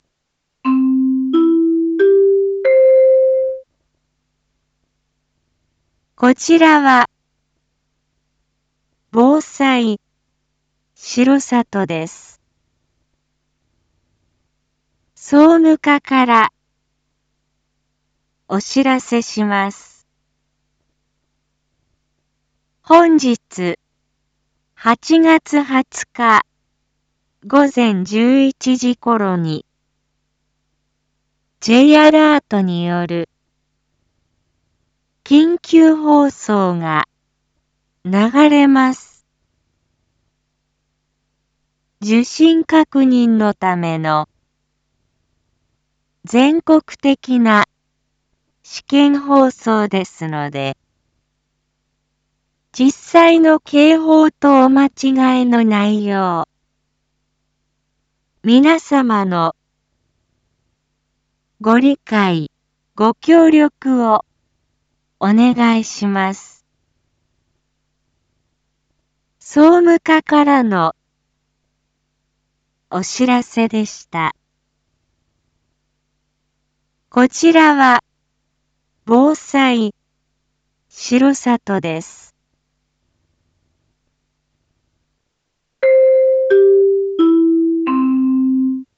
BO-SAI navi Back Home 一般放送情報 音声放送 再生 一般放送情報 登録日時：2025-08-20 07:01:31 タイトル：Jアラート（全国瞬時警報システム）訓練のための放送配信 インフォメーション：本日、８月２0日 午前１１時ころに、Ｊアラートによる緊急放送が流れます。